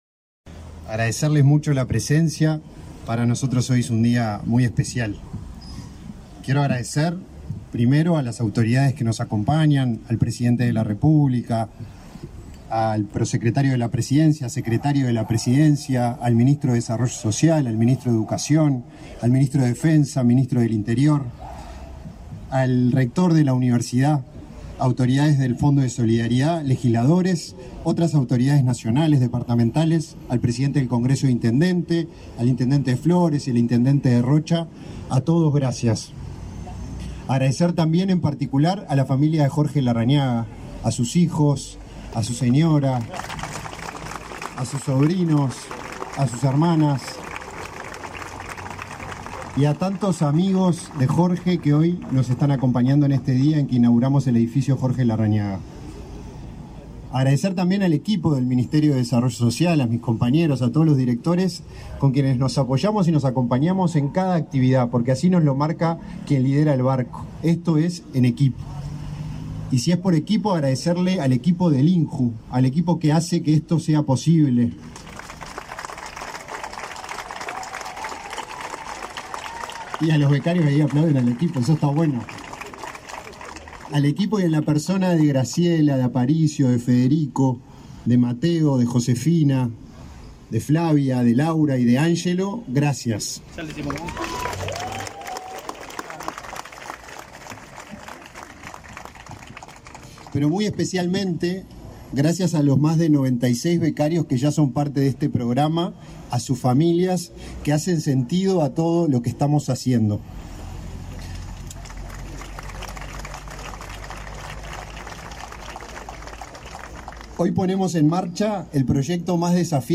Conferencia de prensa por la inauguración del edificio Jorge Larrañaga para estudiantes del interior del país
Conferencia de prensa por la inauguración del edificio Jorge Larrañaga para estudiantes del interior del país 24/10/2022 Compartir Facebook X Copiar enlace WhatsApp LinkedIn Con la presencia del presidente de la República, Luis Lacalle Pou, se inauguró, este 24 de octubre, el edificio Jorge Larrañaga, en el marco del programa Ciudad Universitaria, del Instituto Nacional de la Juventud (INJU) del Ministerio de Desarrollo Social (Mides). Participaron del evento, el director de INJU, Felipe Paullier; el presidente del Congreso de Intendentes, Guillermo López, y el ministro del Mides, Martín Lema.